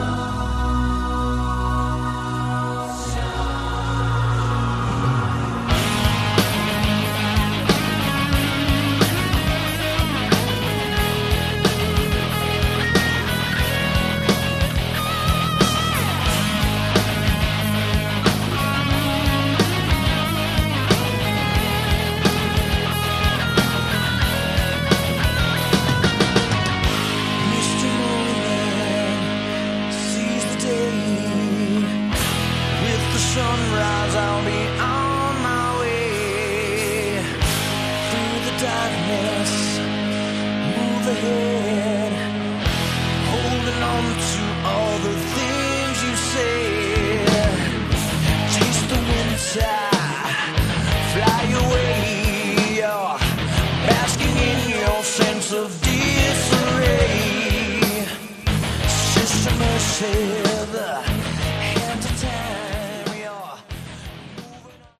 Category: Melodic Metal
guitar, keyboards and vocals